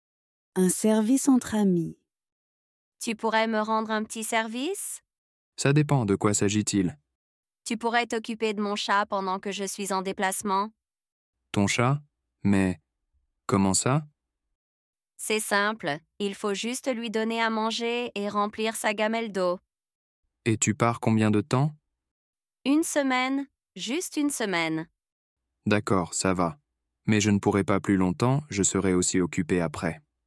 Dialogue en français – Un service entre amis (Niveau A2)